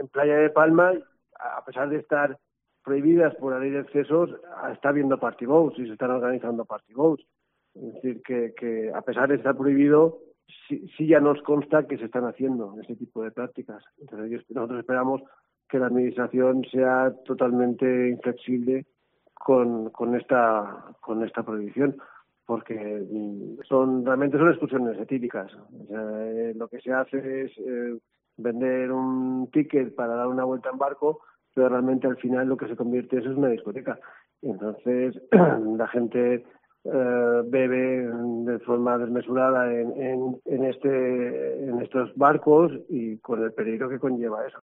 CORTE DE VOZ ABONE